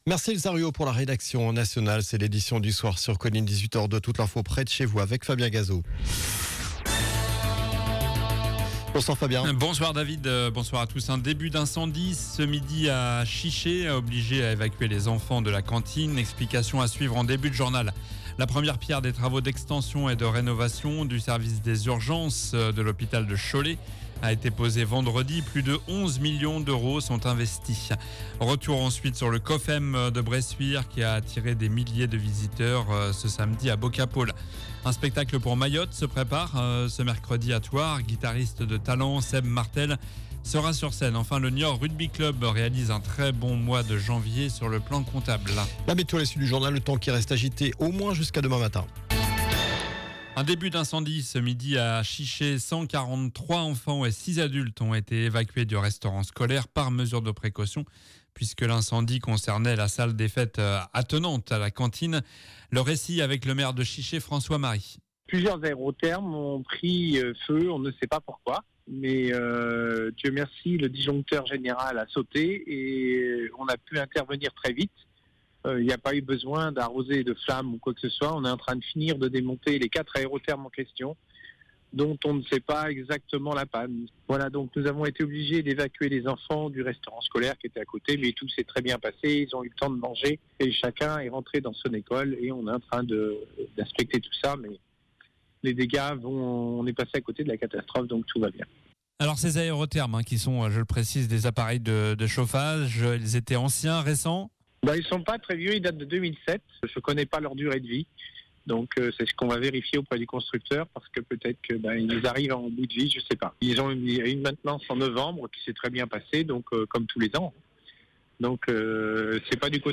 Journal du lundi 27 janvier (soir)